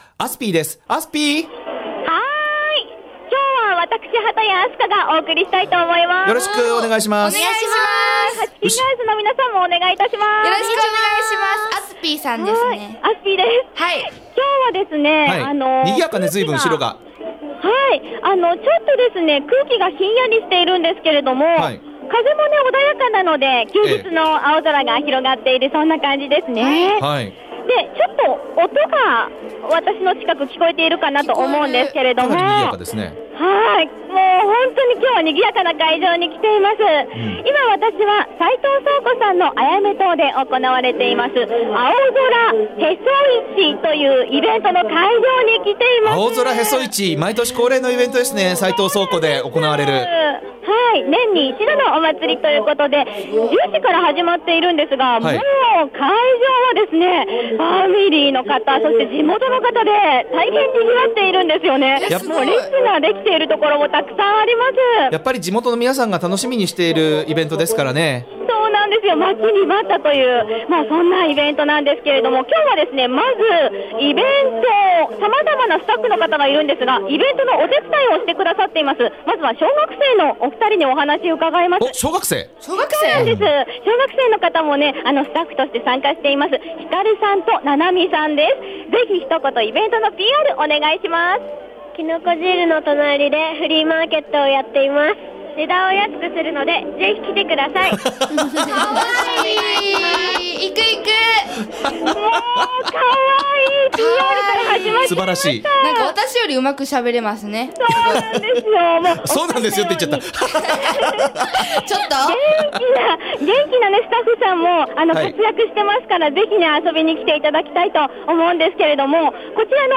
元気いっぱいのアイドルグループ 「はちきんガールズ」さんもスタジオで登場して下さってますっ☆